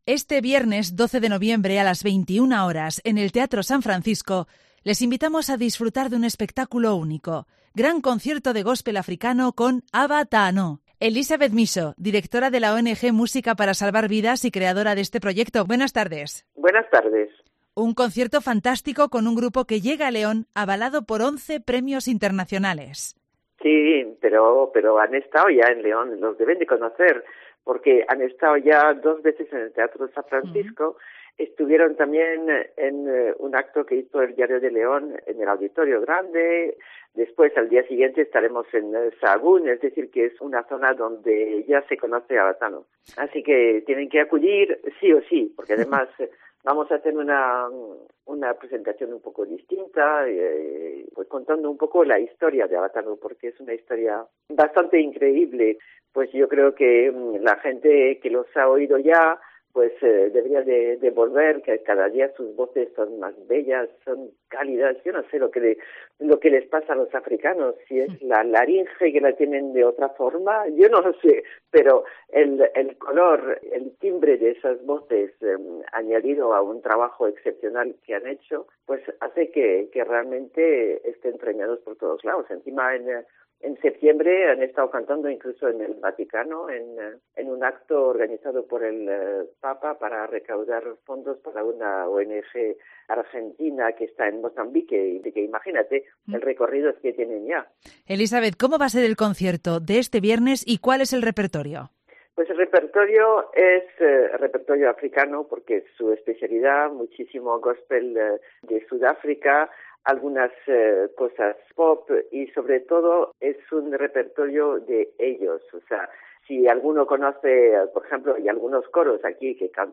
Música gospel africana con "Erase una vez...Aba Taano" en el Teatro San Francisco
El teatro San Francisco presenta el viernes 12 de noviembre a las 21 h. el gran concierto Solidario de Góspel Africano y Pop con el quinteto Aba Taano.
Aba Taano son cinco voces cálidas que transmiten alegría y dinamismo.
Cantos a cappella escenificados, movidos y amenos.